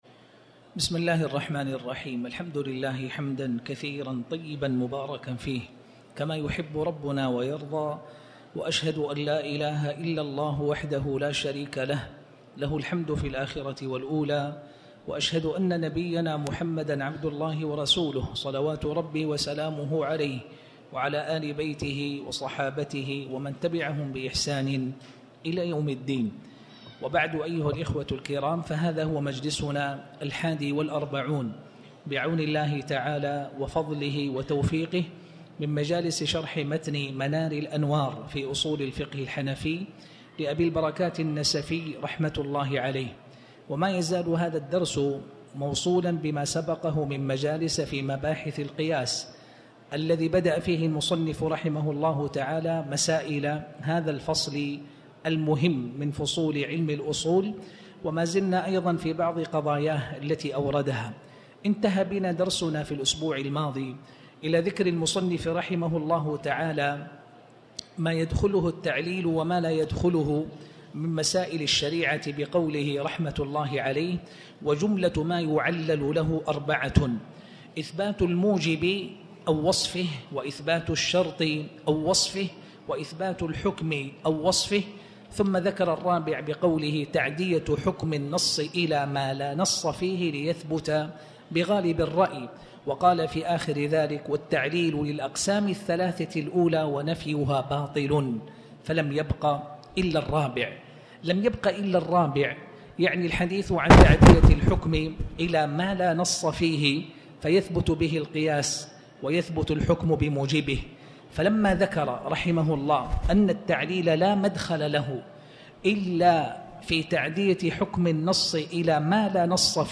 تاريخ النشر ١٩ ربيع الثاني ١٤٤٠ هـ المكان: المسجد الحرام الشيخ